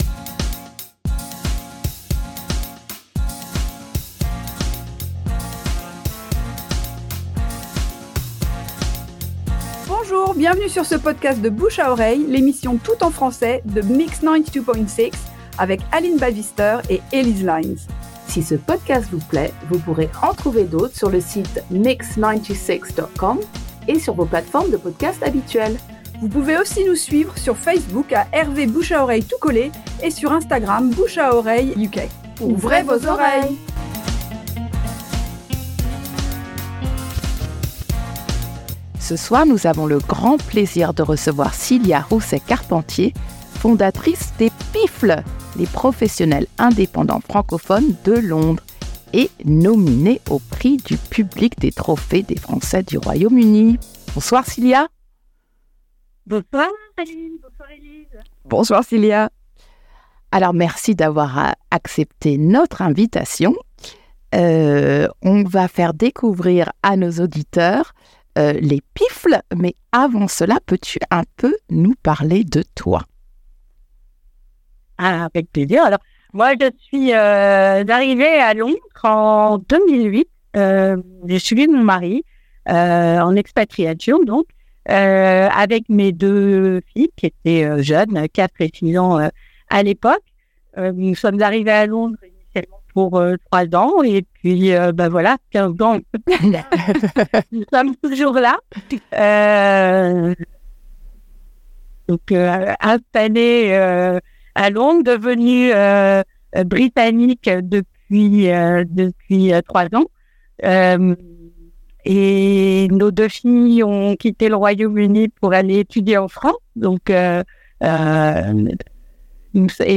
Extrait d’une émission diffusée en octobre 2023 sur Mix ...